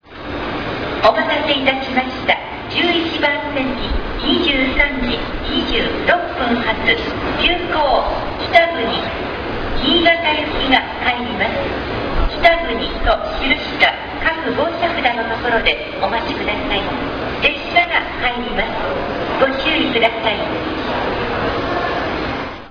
23時06分、閑散とした大阪駅11番ホームに、急行きたぐに新潟行が入線してきた。
大阪駅 急行きたぐに入線アナウンス